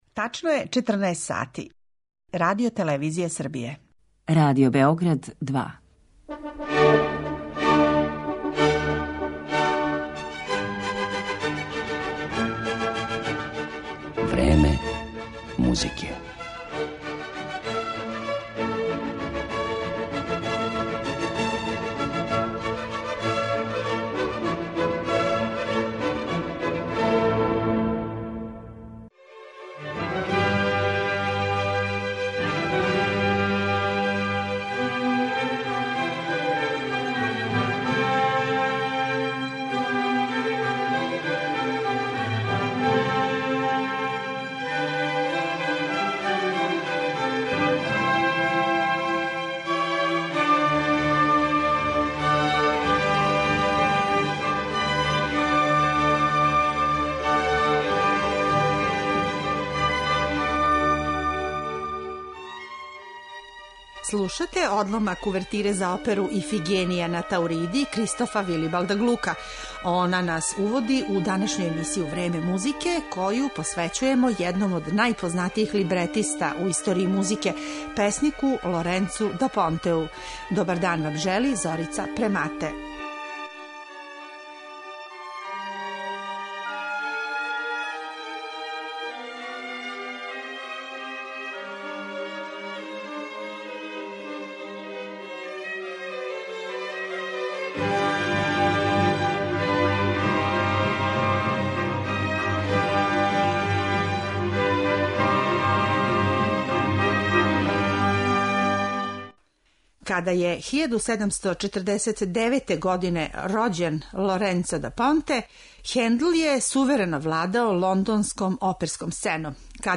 Уз одломке из опера Салијерија и Моцарта, чућете и причу о Да Понтеовом дугом пустоловном животу, пуном преокрета.